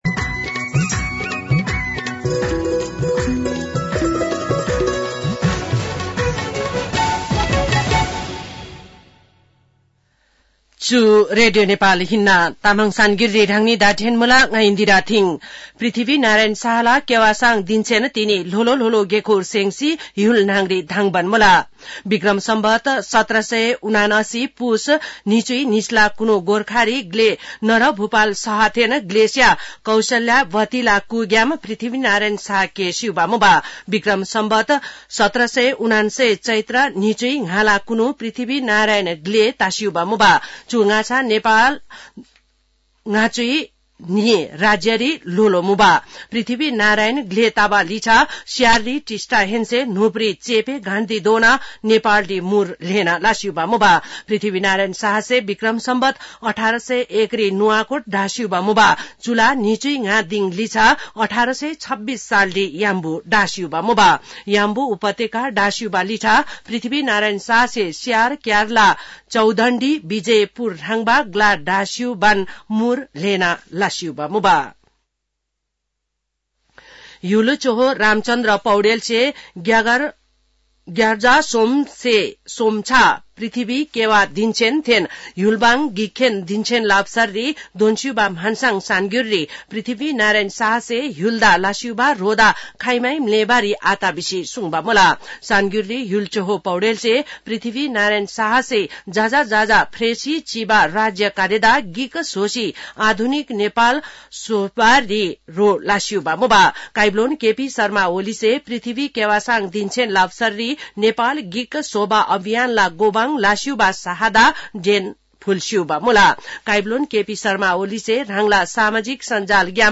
An online outlet of Nepal's national radio broadcaster
तामाङ भाषाको समाचार : २८ पुष , २०८१